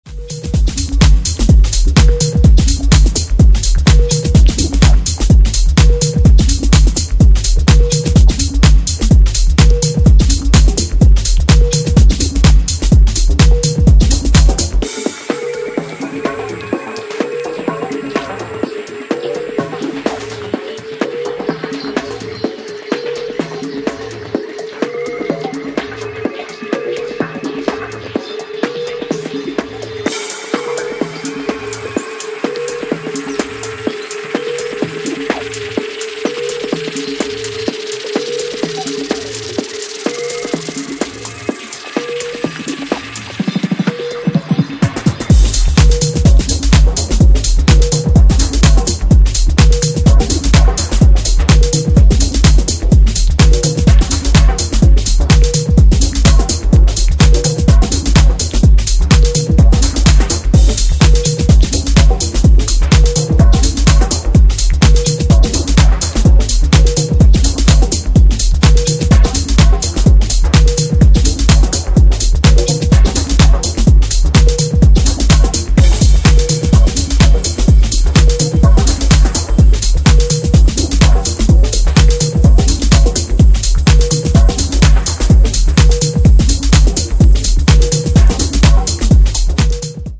ジャンル(スタイル) TECH HOUSE / DEEP HOUSE